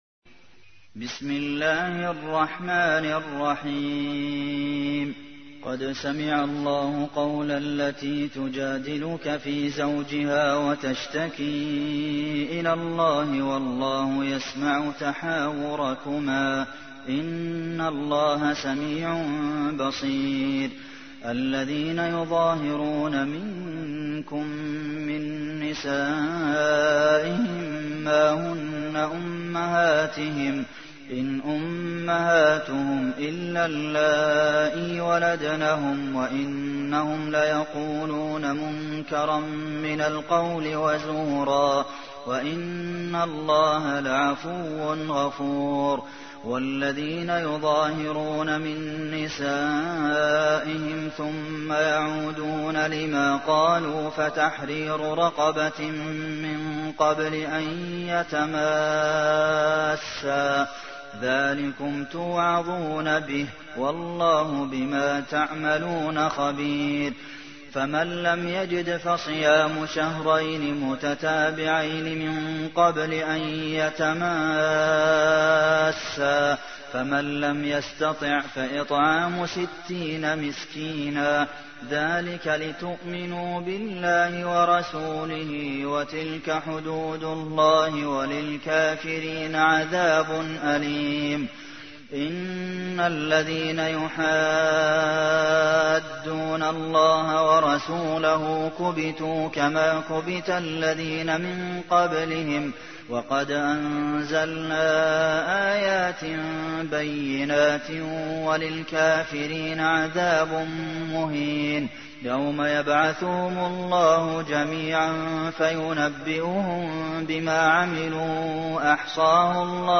تحميل : 58. سورة المجادلة / القارئ عبد المحسن قاسم / القرآن الكريم / موقع يا حسين